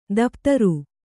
♪ daptaru